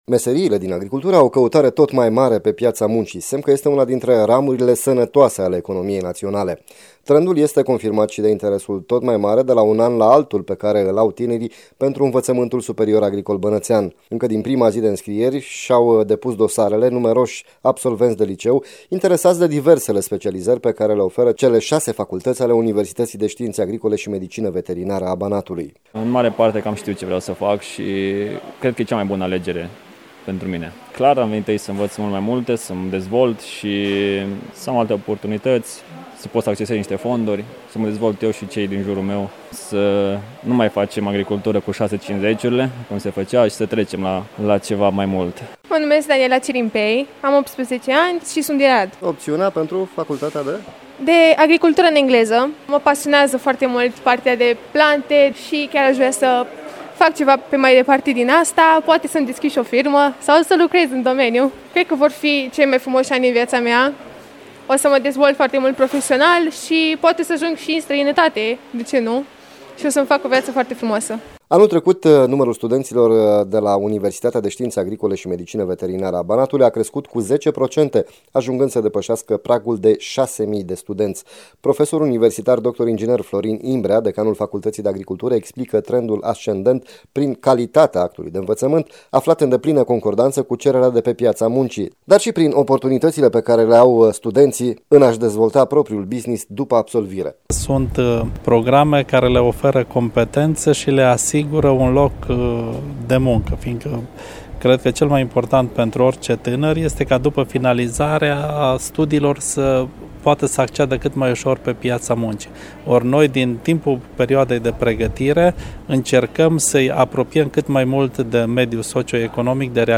Cum arată anul acesta, aflați din reportajul